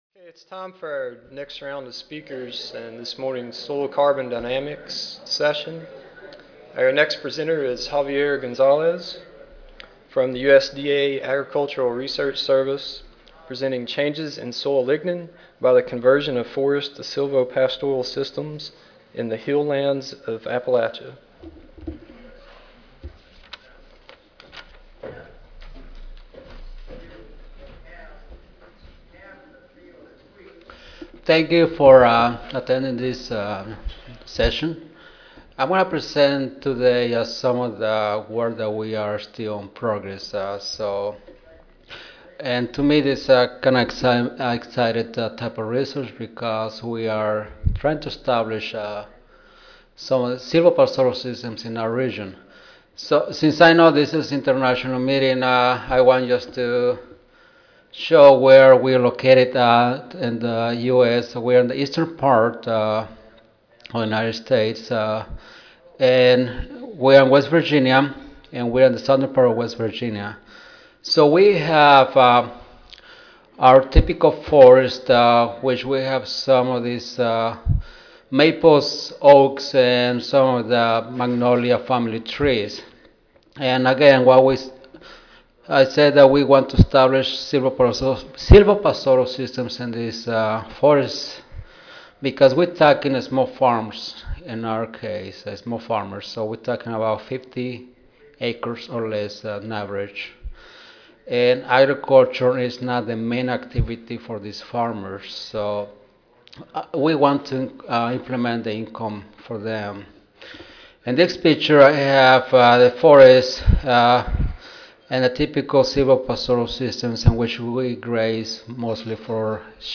WV Audio File Recorded presentation Establishment of silvopastoral systems in Appalachia has potential to increase farm income. Required fertilization and liming for the silvopastoral systems modifies soil chemical properties, including formation and stabilization of soil organic matter though the impact is unknown.